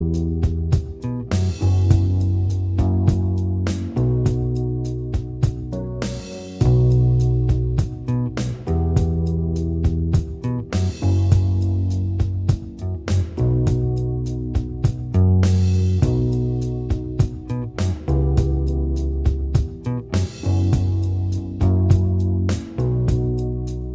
Minus Acoustic Guitars Soft Rock 5:36 Buy £1.50